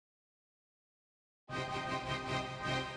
06 strings pick-up.wav